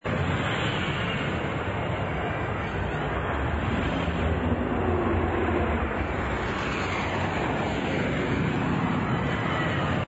ambience_outside_traffic.wav